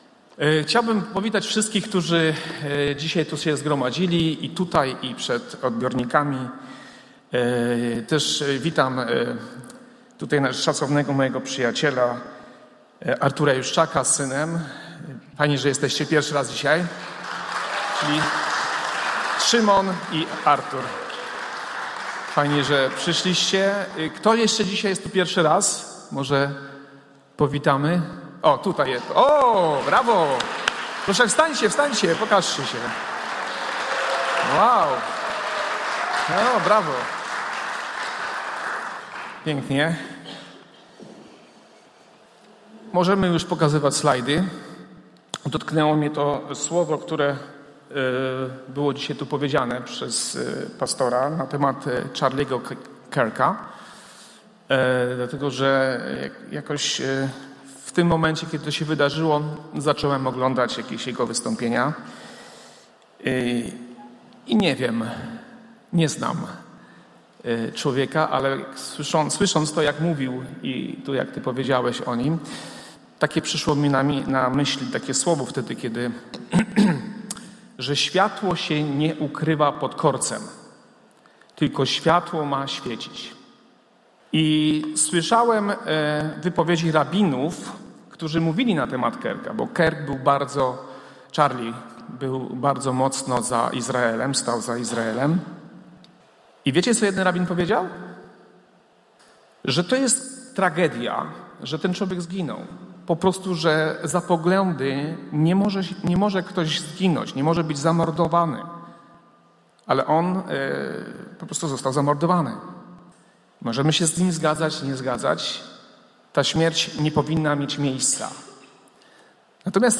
Pytania do przemyślenia po kazaniu: 1) Jak inwestować w Wieczność? 2) Kim jesteśmy w oczach Boga? 3) Czy wierzący staną przed sądem?